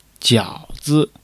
jiao3--zi.mp3